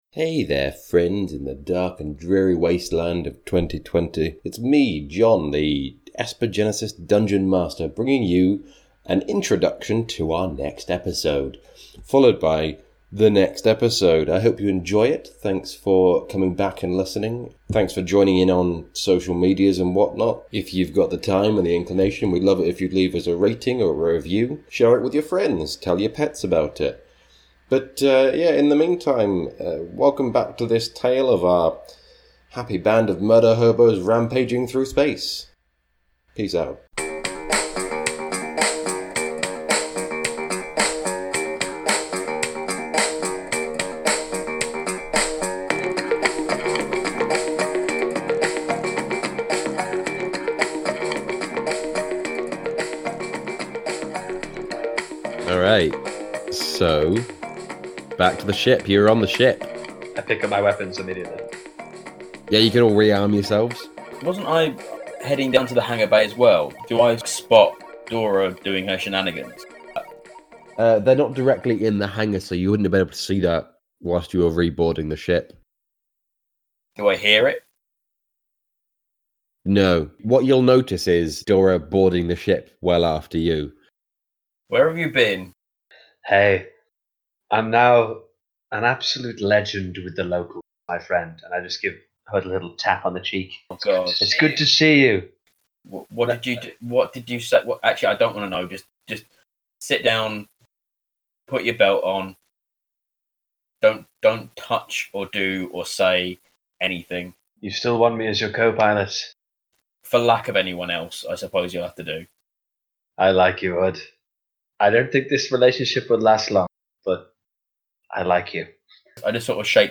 Welcome to our Esper Genesis podcast, telling the brand spanking new story of an unlikely group of galactic nobodies caught up in something far bigger than they expected.